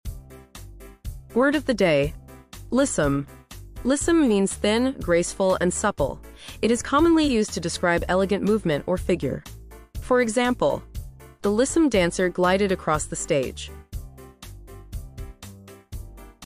Pronunciation: /ˈlɪs.əm/